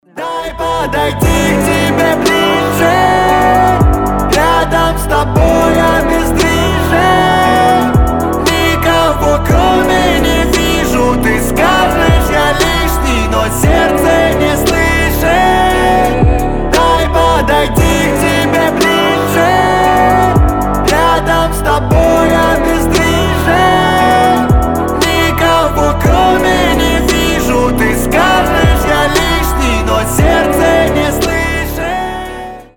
мужской вокал
лирика
русский рэп
медленные